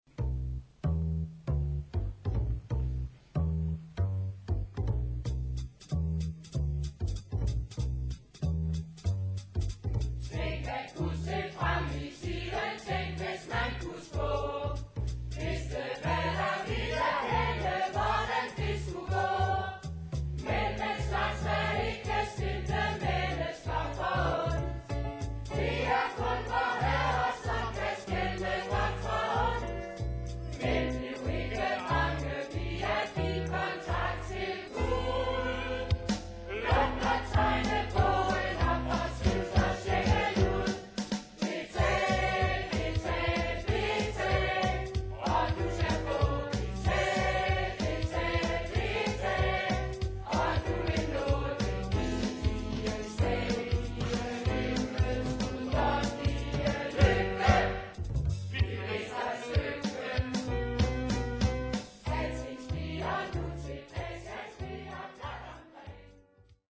"live on stage!"